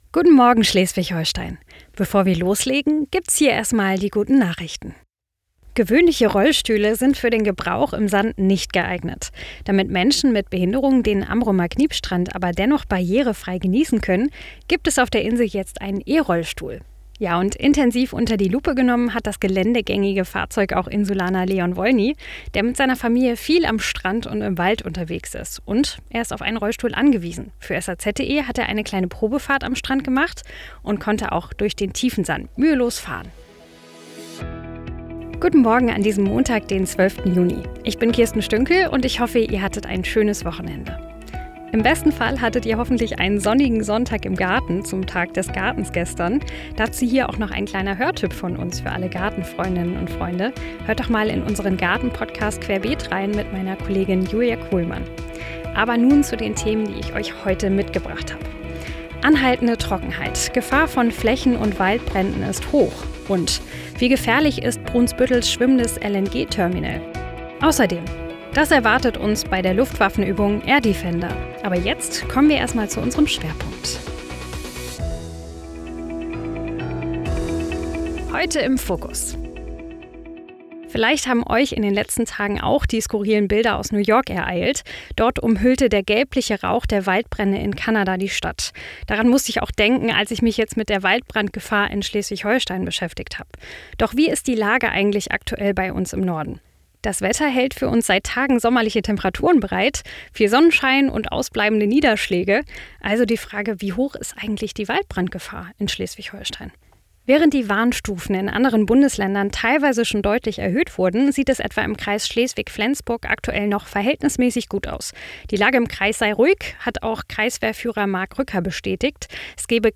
Guten Morgen SH — Dein neuer News-Podcast für Schleswig-Holstein
Nachrichten